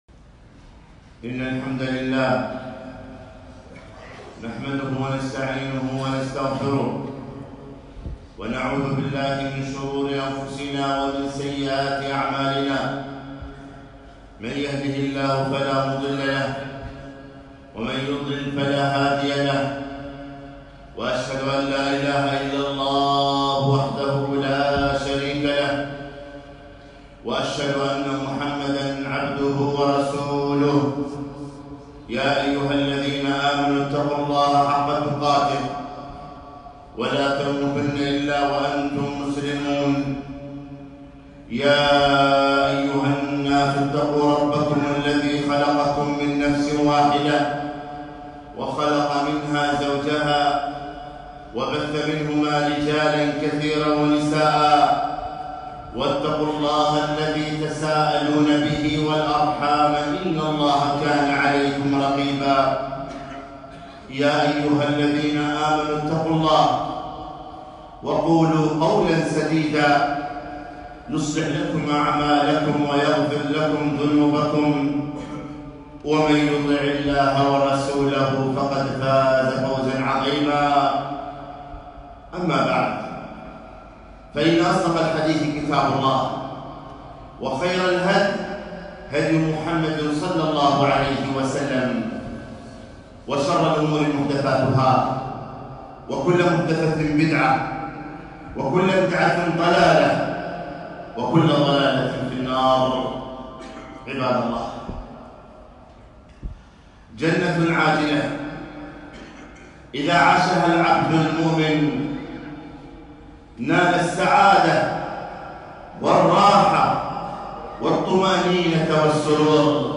خطبة - الثقة بالله تعالى